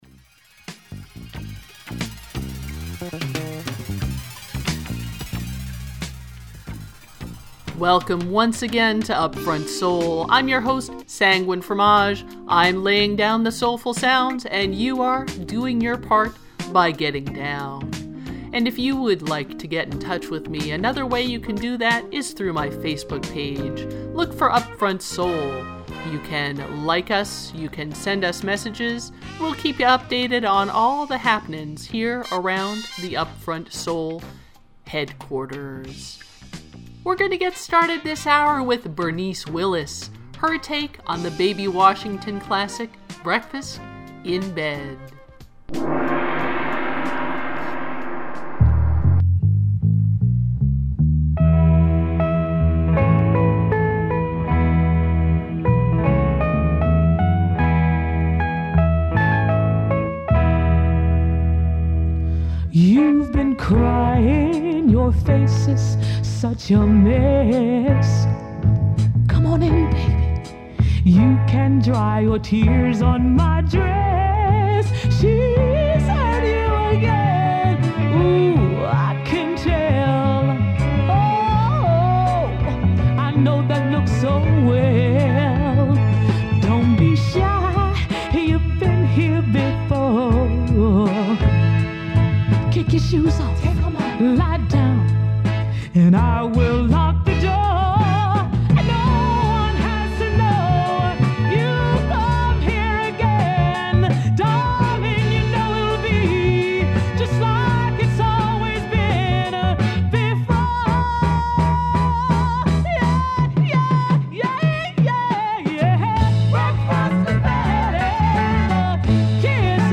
Funk, soul, and jazz
120 minutes of soulful sounds to which you may get down.